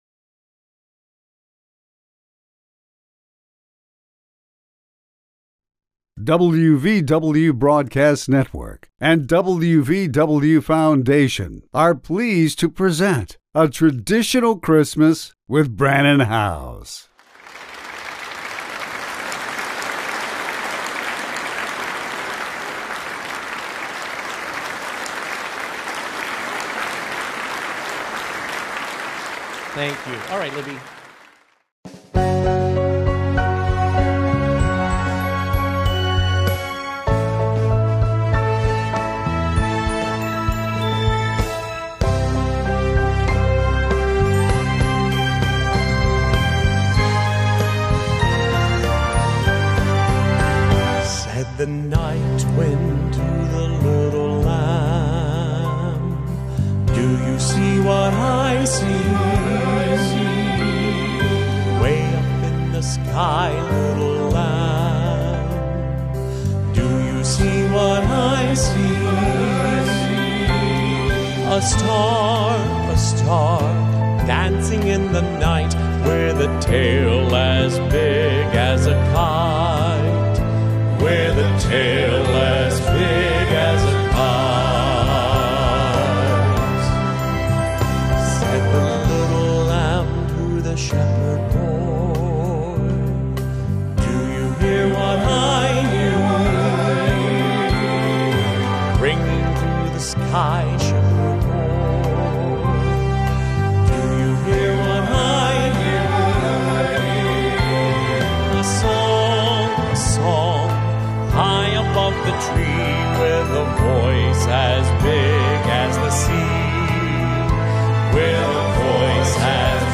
Traditional Christmas Concert LIVE From Lake of the Ozarks 2022